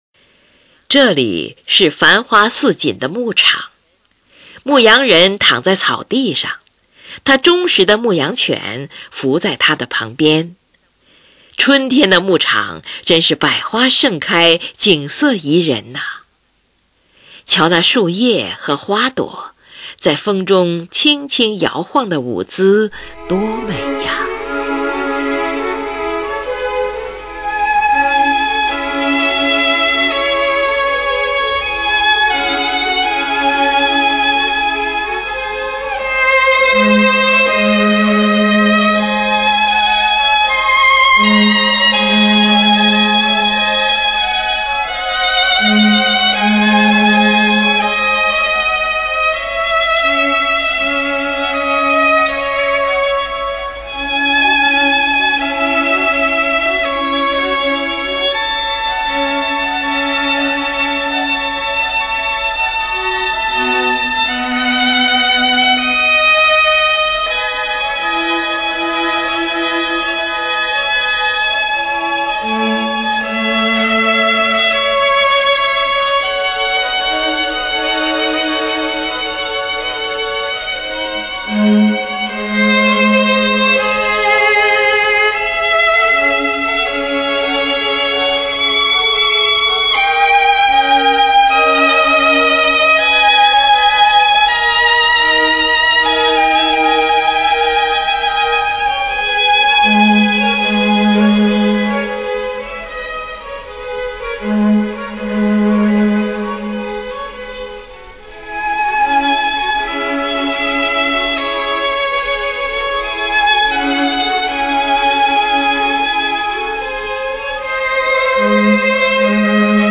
小提琴协奏曲
春天--E大调
2. 广板，表现在繁花似锦的牧场上，树叶轻柔地低语，牧羊人在牧羊犬旁沉睡。
有小提琴演奏的沉睡的牧羊人旋律贯穿全曲；中提琴的短小动机表现牧羊犬在叫；而两部小提琴摇曳的音型代表树叶的低语。